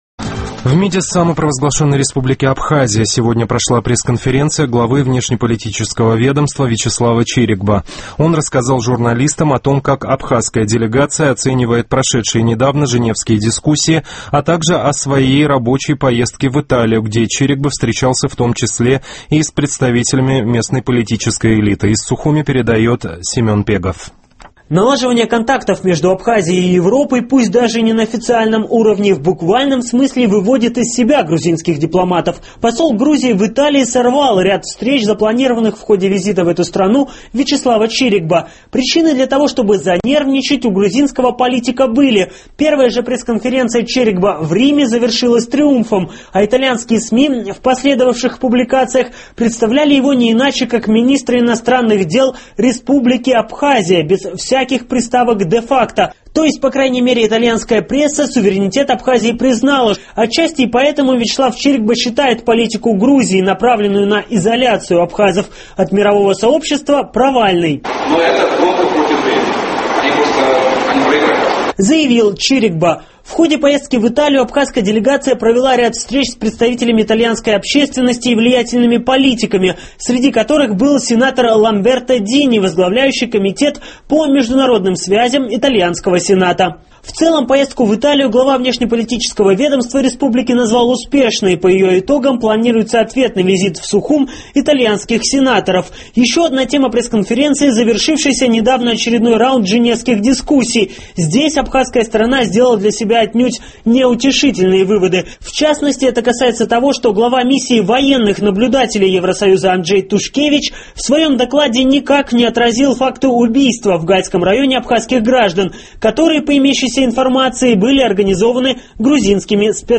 В МИДе Абхазии прошла пресс-конференция главы внешнеполитического ведомства Вячеслава Чирикба.